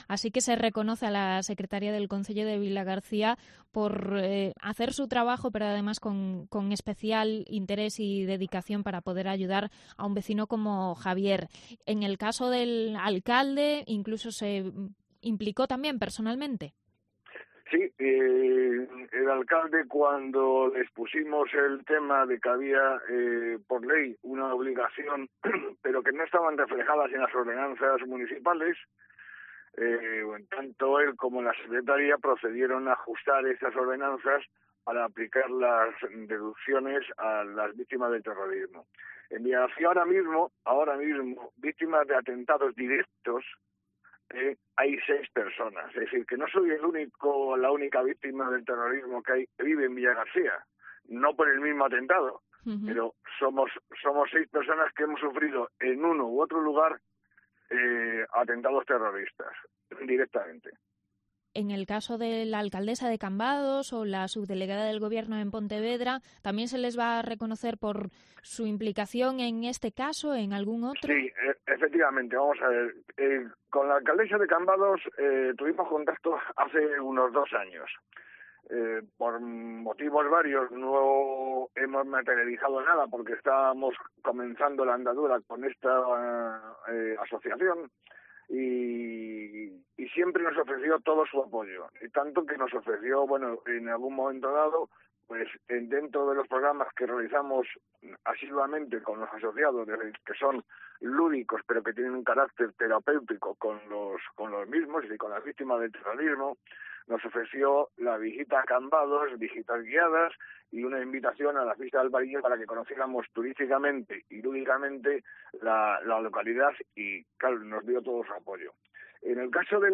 Entrevista sobre el apoyo a las víctimas del terrorismo en las Rías Baixas